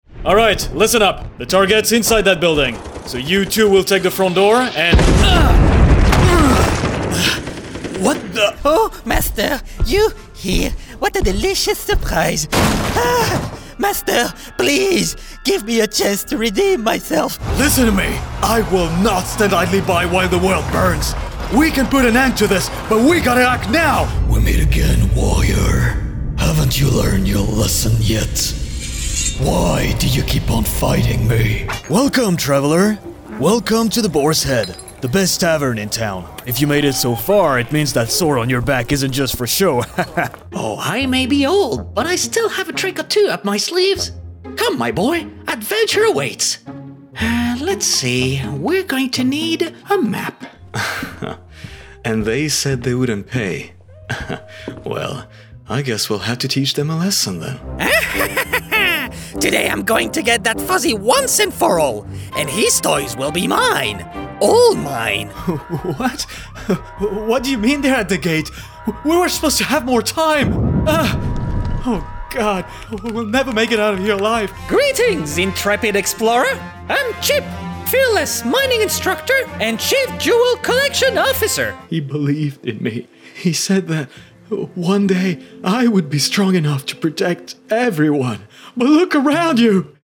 Voice actor since 2016 - French + French-accented & International English
Character Reel
French from France / International English / French-accented English
Young Adult
Middle Aged